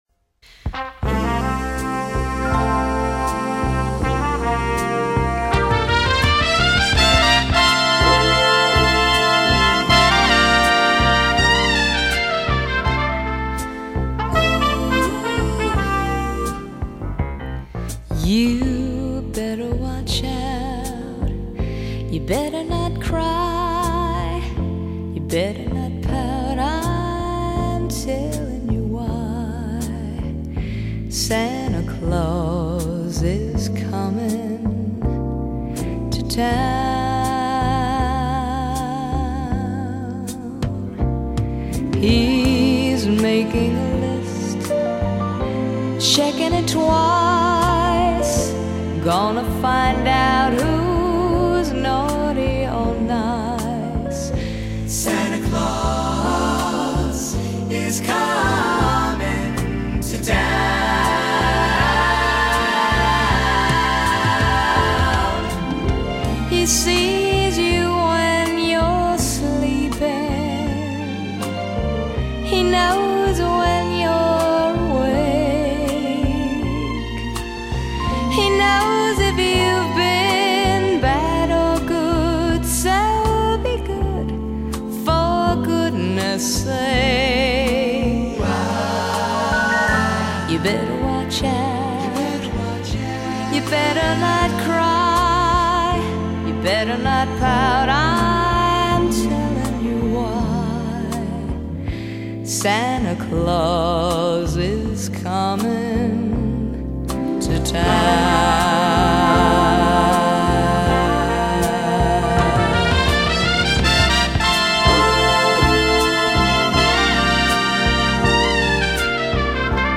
音乐类型： Pop, Christmas, PIANO　　　　 .